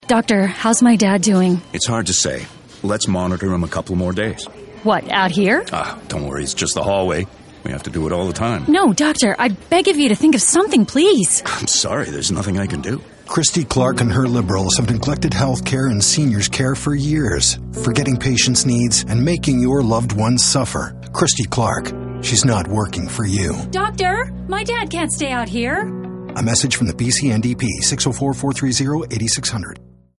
Radio ad 1 - Corridor